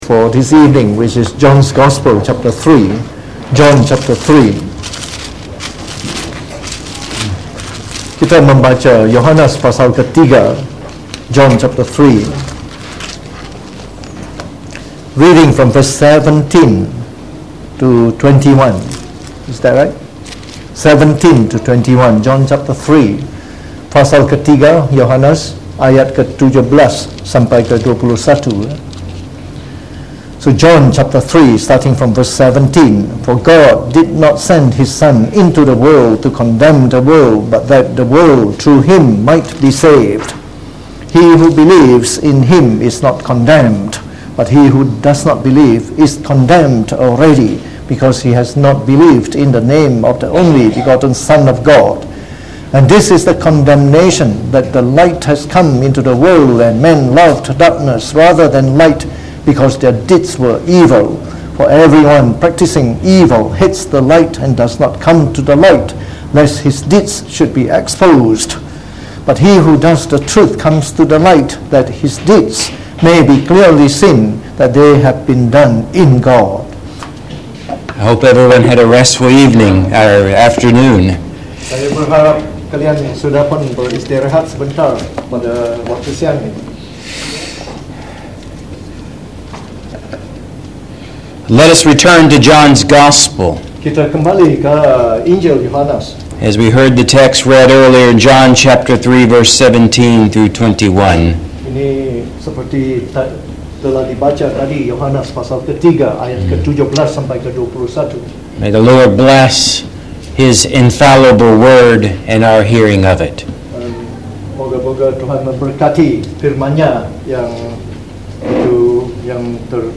delivered in the Evening Service